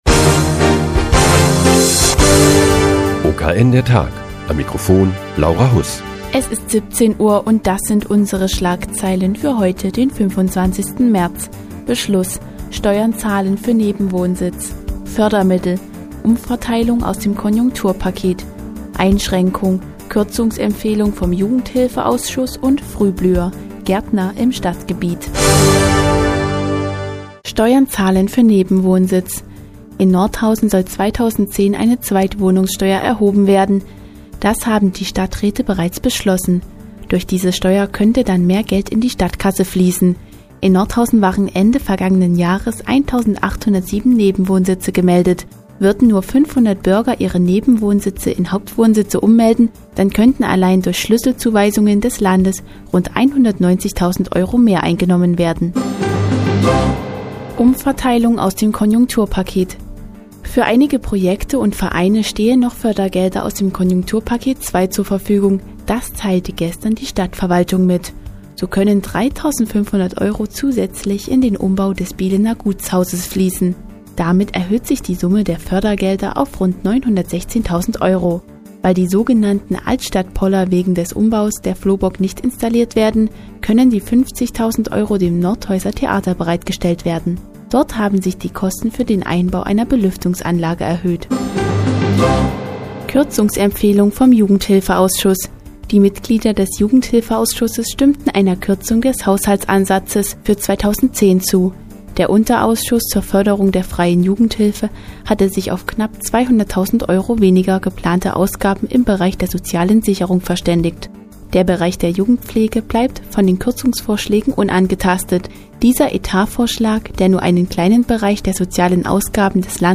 Die tägliche Nachrichtensendung des OKN ist nun auch in der nnz zu hören. Heute geht es um die Zweitwohnungssteuer und Frühblüher im Stadtgebiet Nordhausen.